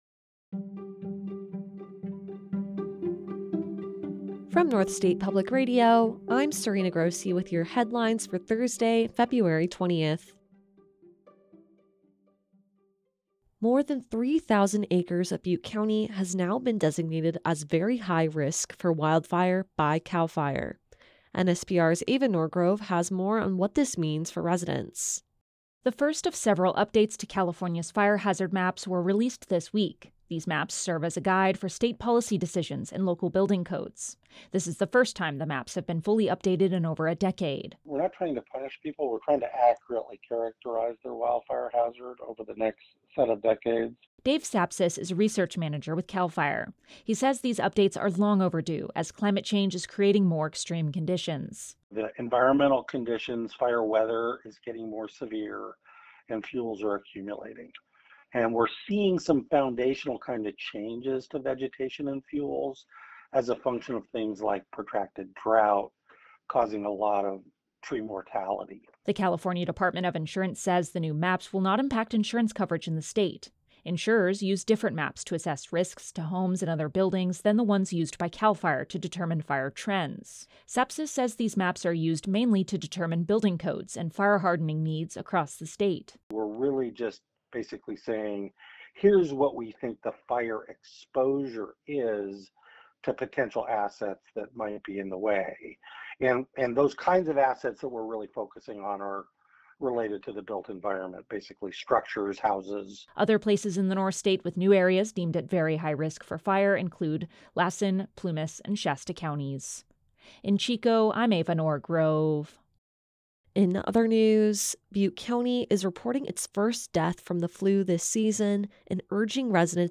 A daily podcast from NSPR featuring the news of the day from the North State and California in less than 10 minutes.